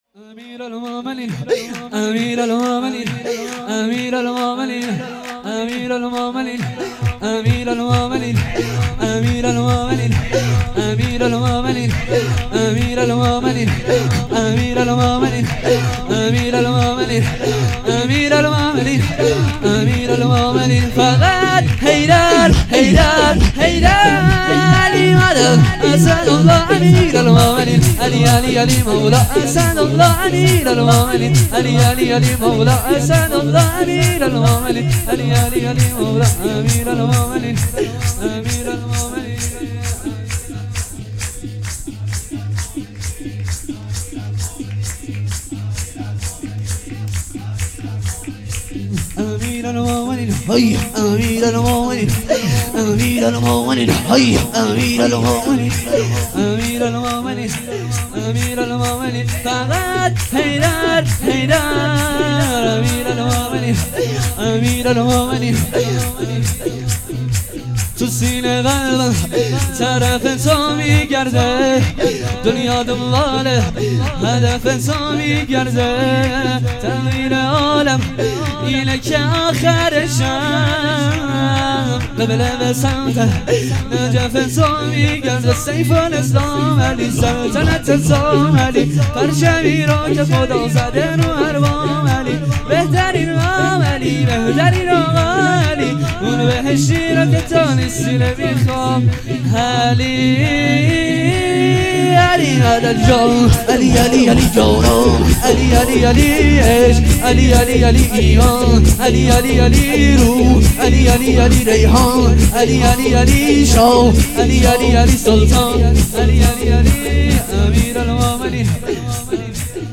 جشن ولادت امام حسن عسکری (ع) ۱۴۰۱